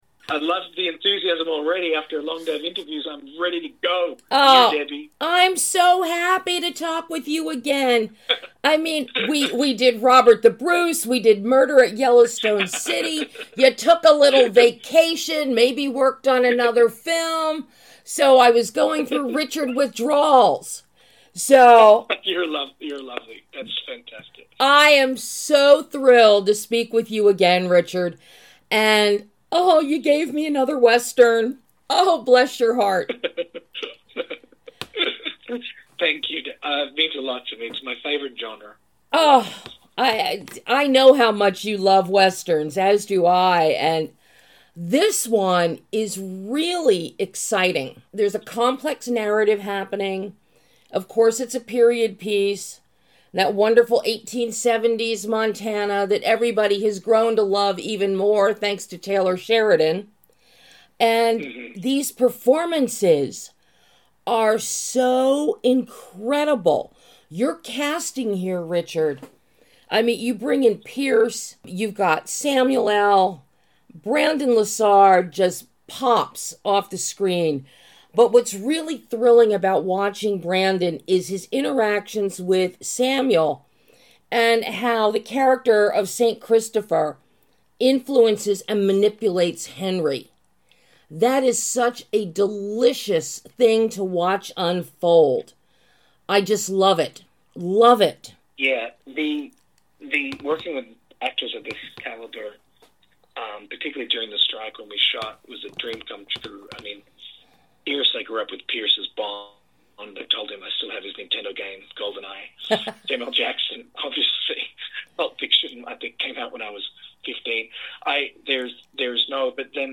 THE UNHOLY TRINITY - Exclusive Interview